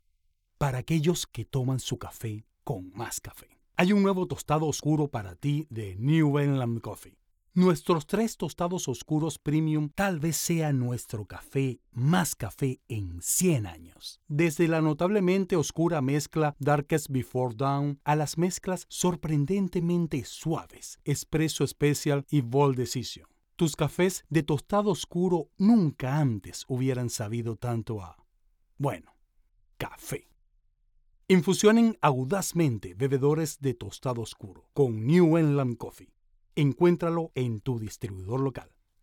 Male
Adult (30-50)
Mi acento nativo es Venezolano, con manejo del acento neutro, mi voz puede ser versátil y agradable, con una buena dicción y conocimiento profesional y técnico de la locucion, junto a la creatividad, flexibilidad y empatía con tus ideas.
Television Spots
0401Bold_Coffee_Spot_-_Spanish.mp3